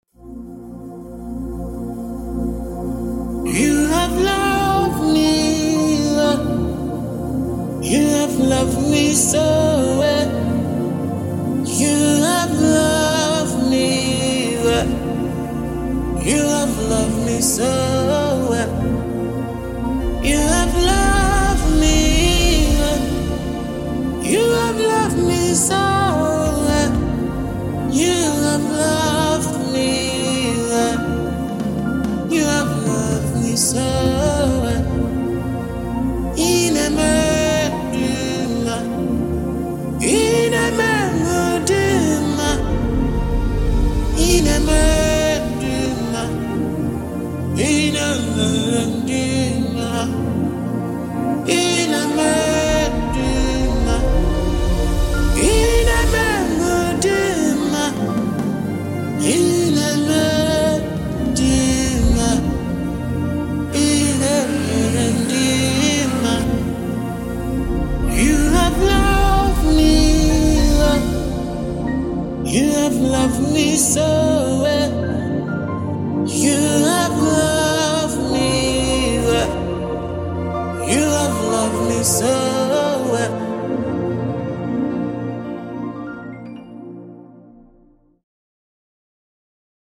heartfelt gospel song
With inspiring lyrics and a worshipful atmosphere